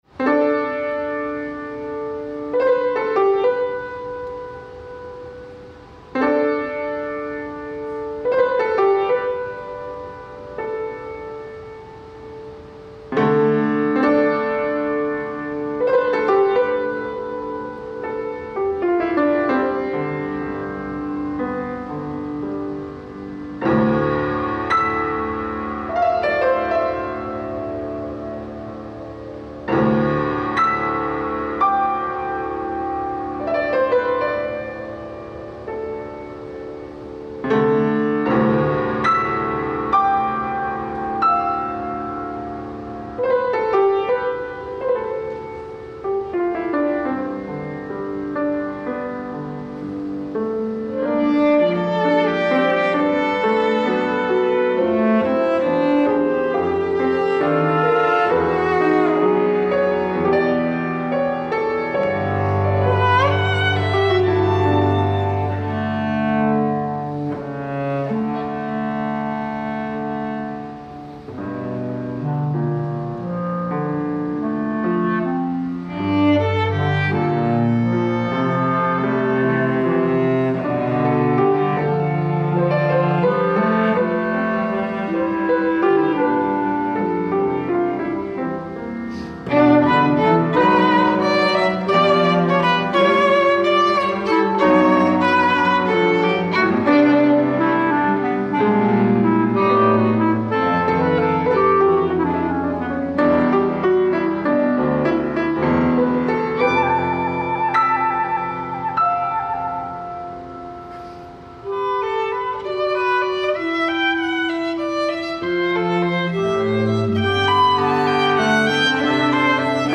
Reflections on a Hildegard Refrain for clarinet, violin, cello, and piano, 2018
Performed by Unheard-of//Ensemble